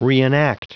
Prononciation du mot reenact en anglais (fichier audio)
Prononciation du mot : reenact